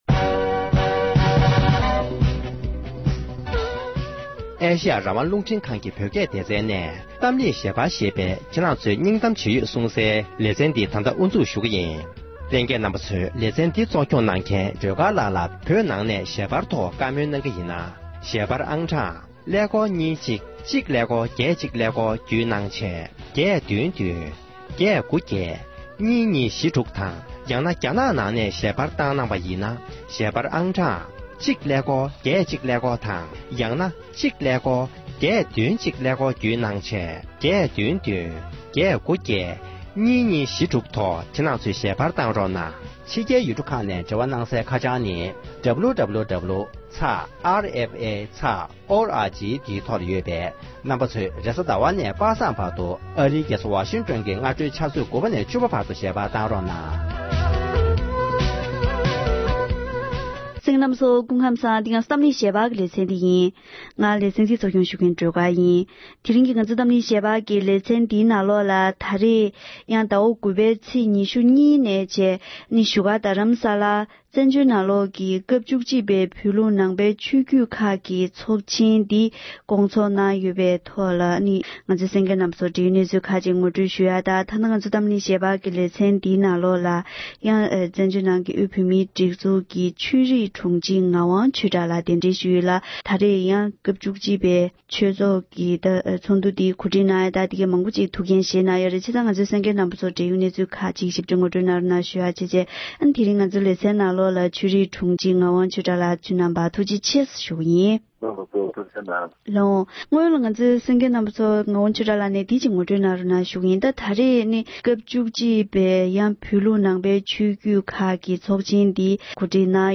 བོད་ཕྱི་ནང་གཉིས་ཀྱི་བོད་མི་དང་བཀའ་མོལ་ཞུས་པ་ཞིག་གསན་རོགས་ཞུ༎